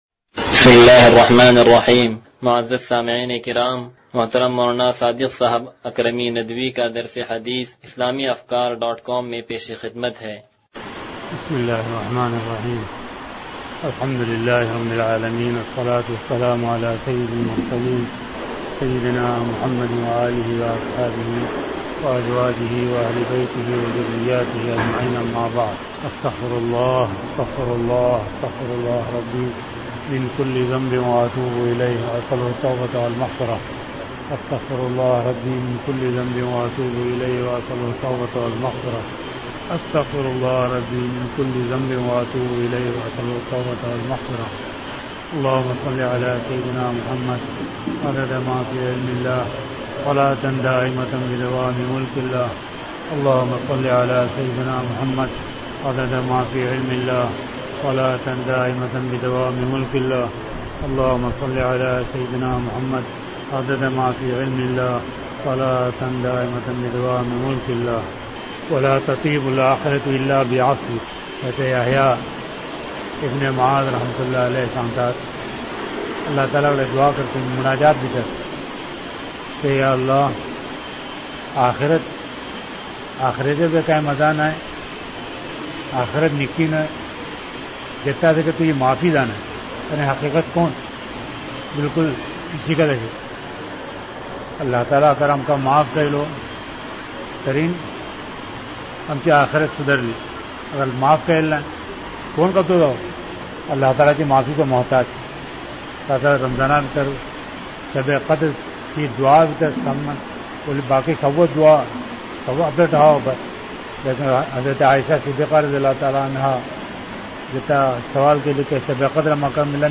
درس حدیث نمبر 0140